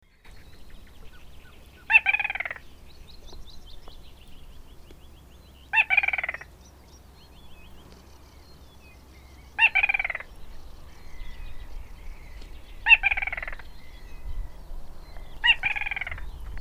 Little Crake Porzana parva
Pukkila, Finland, 22.5.2010
Lauantaina olin kymmenen aikaan aamupäivällä Pukkilan Kanteleenjärvellä, kun kuulin luhdalta aktiivista naaras pikkuhuitin soidinta.
Saturday 22th I was lucky to find a female Little Crake calling at daylight.